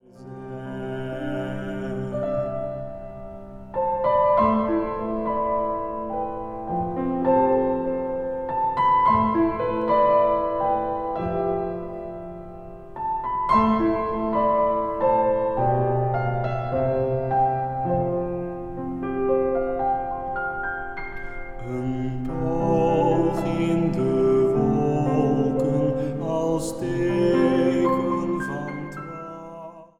meisjessopraan
tenor en cornet
vleugel
orgel.